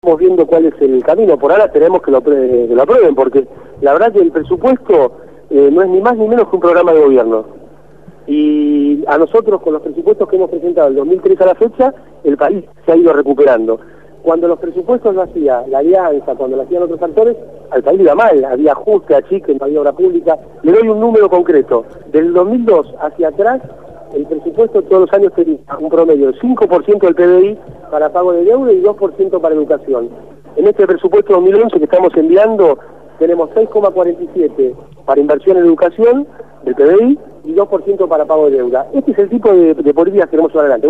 Amado Boudou, Ministro de Economía de la Nación, fue entrevistado